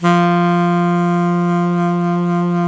SAX ALTOMP02.wav